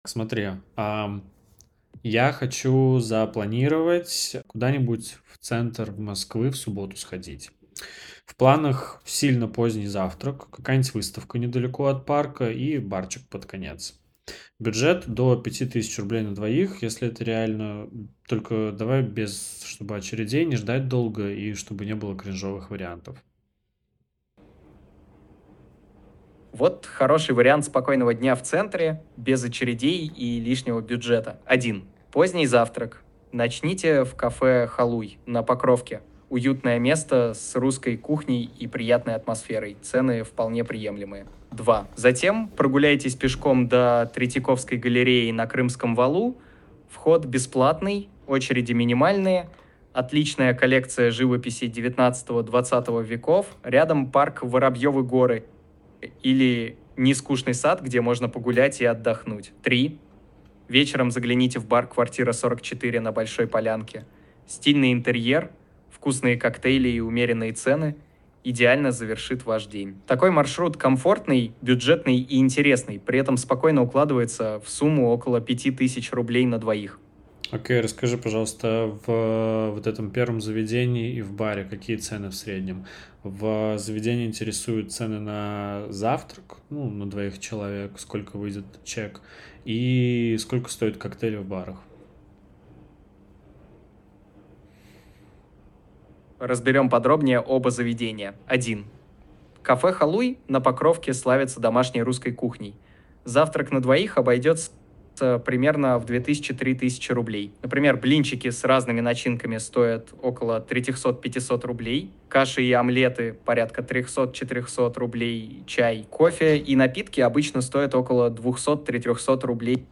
Начал с простого: в реальном времени составил план свидания в выходной день в Москве.